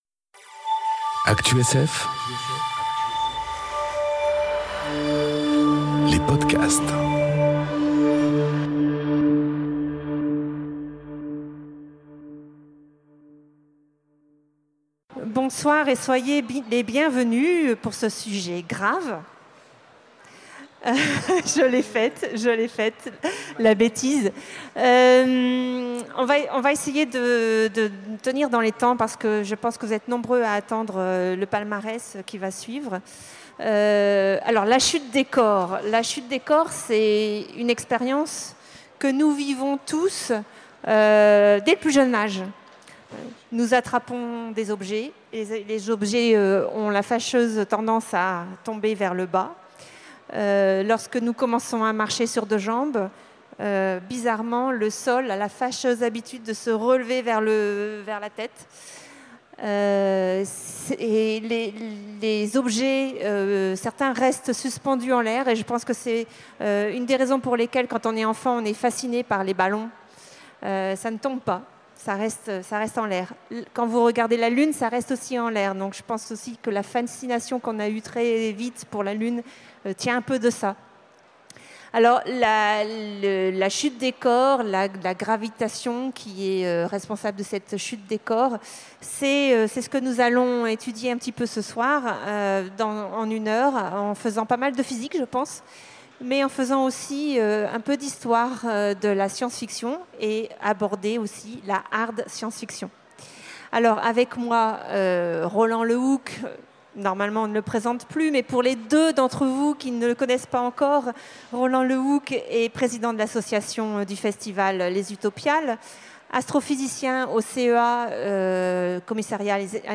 Conférence Tout savoir sur la chute des corps aux Utopiales 2018